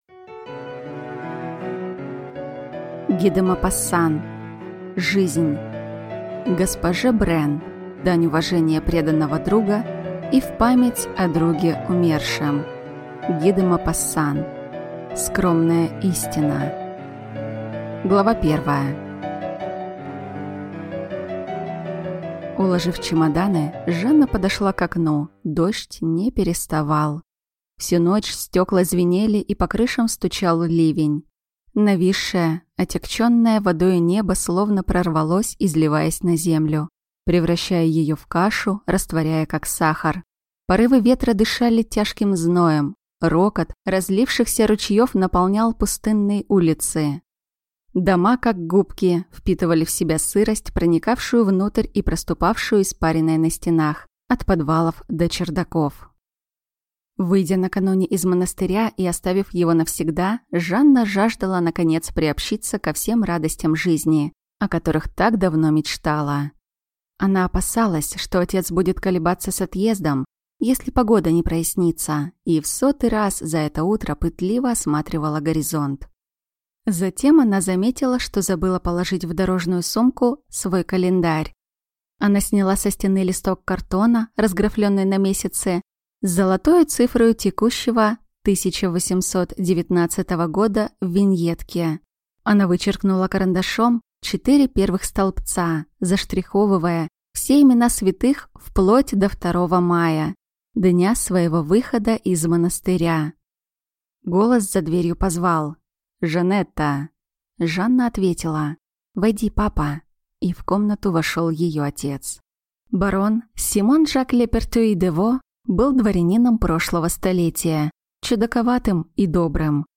Аудиокнига Жизнь | Библиотека аудиокниг